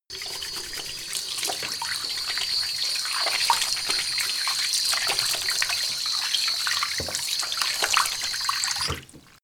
Gemafreie Sounds: Badezimmer